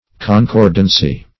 Concordancy \Con*cord"an*cy\, n.